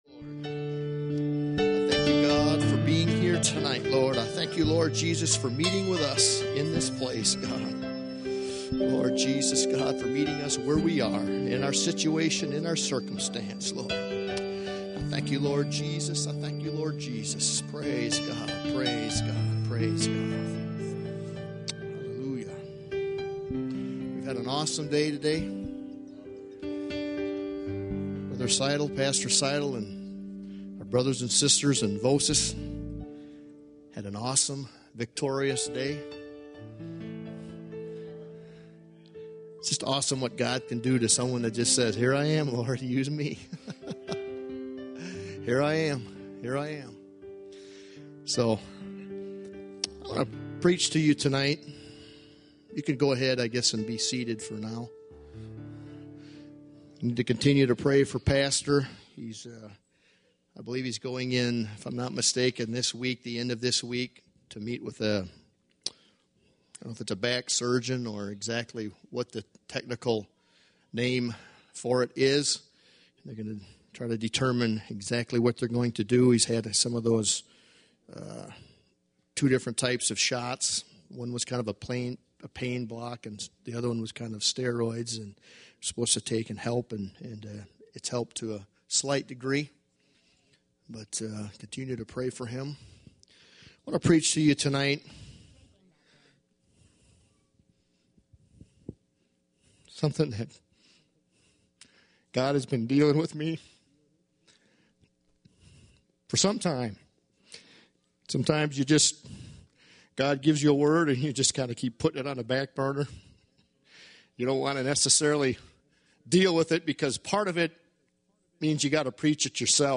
A message from the series "Calvary Gospel Church."